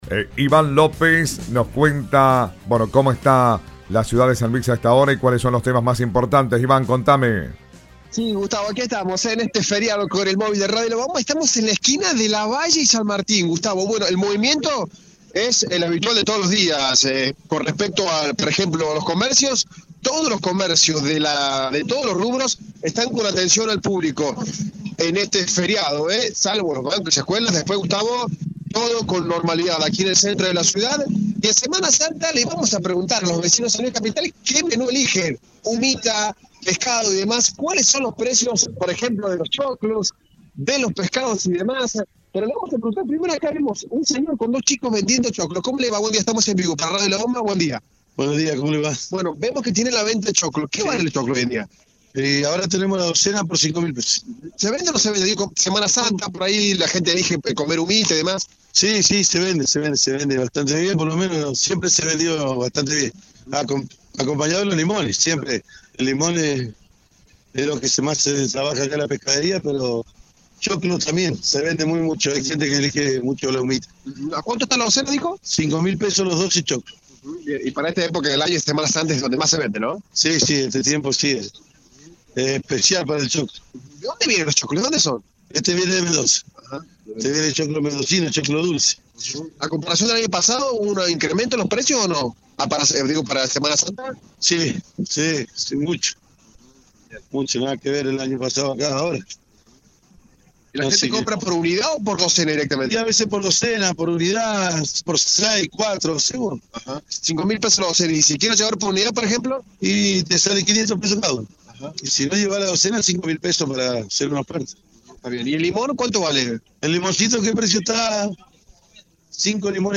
Con el móvil de Radio La Bomba le consultamos a la gente sobre que elijen para almorzar durante Semana Santa y cómo ven los precios.